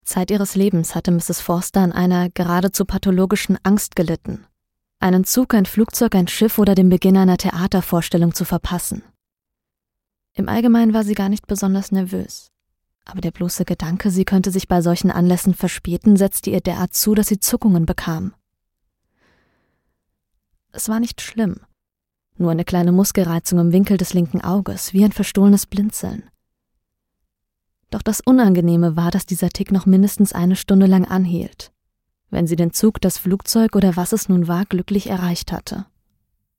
deutsche Sprecherin
mittel tiefe Stimme, warme Klangfarbe
Sprechprobe: Sonstiges (Muttersprache):